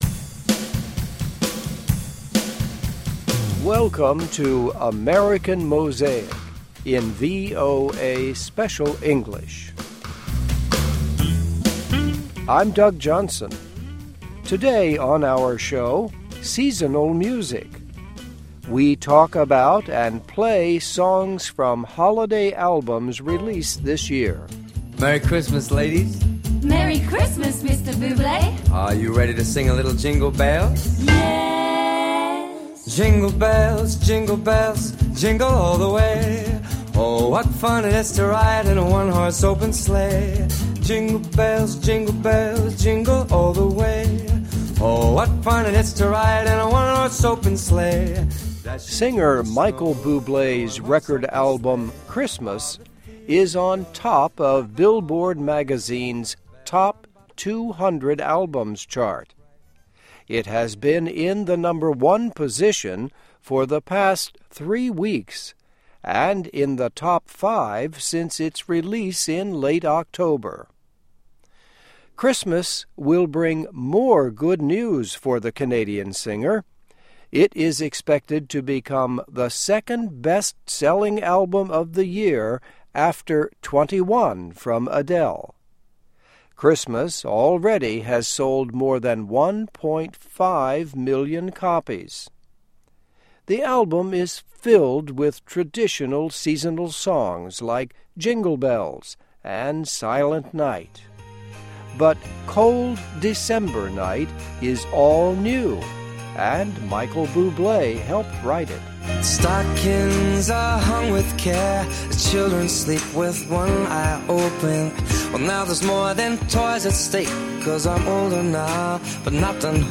We talk about and play songs from holiday albums released this year.